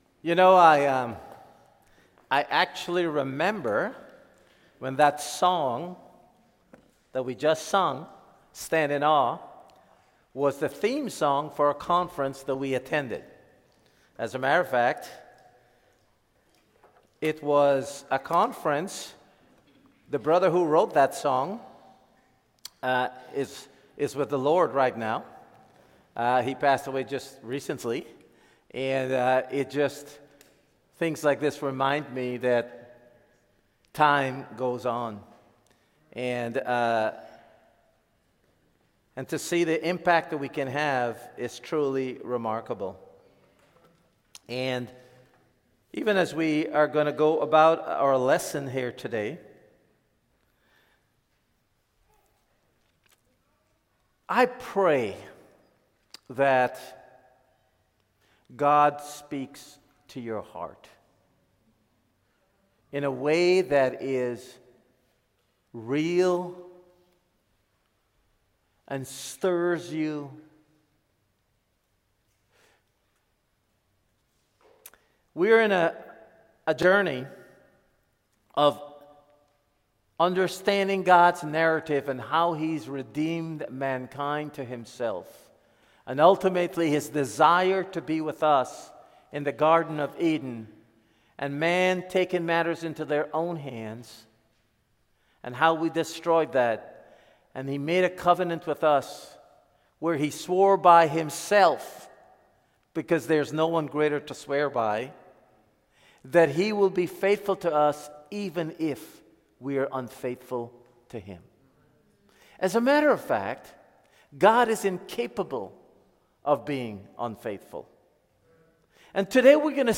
Podcast feed for sermons from Ottawa Church of Christ